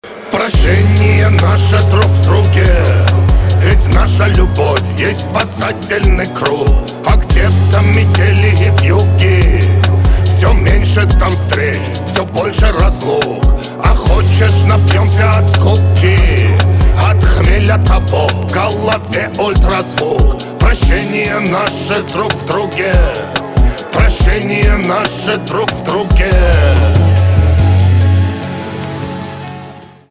русский рэп
битовые , басы , гитара